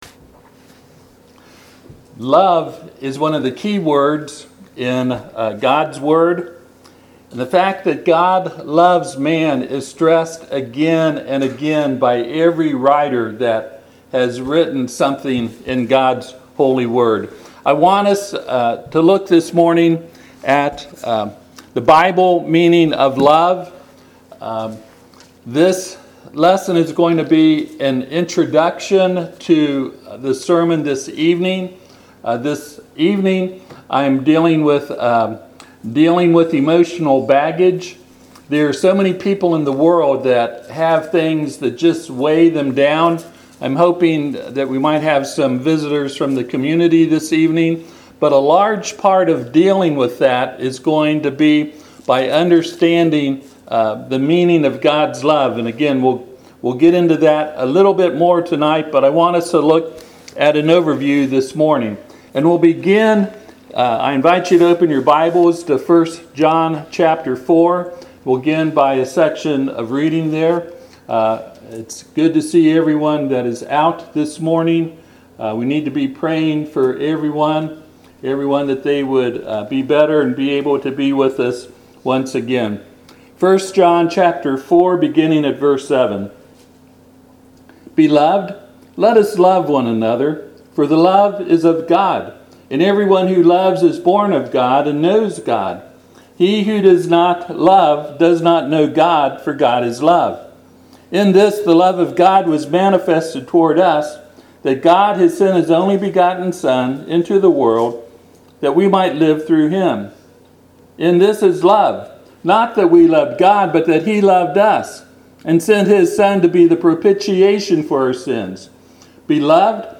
Passage: 1 John 4:7-19 Service Type: Sunday AM